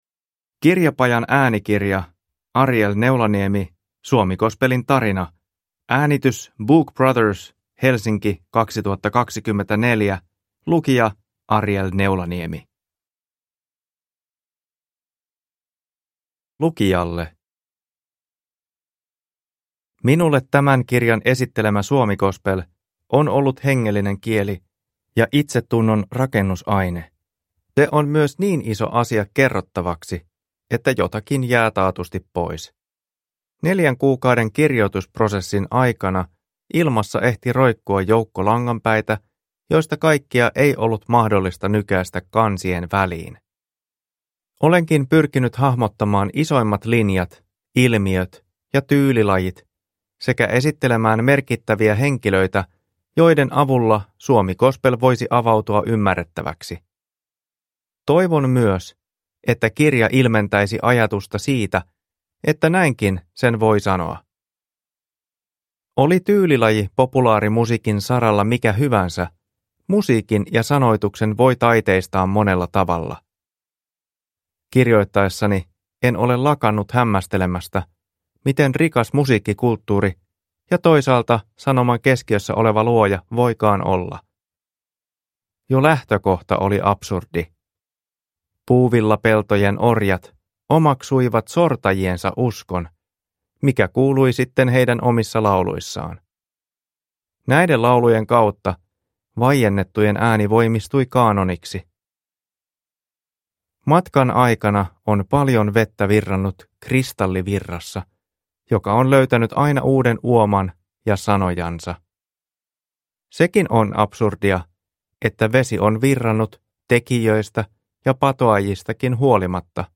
Suomigospelin tarina – Ljudbok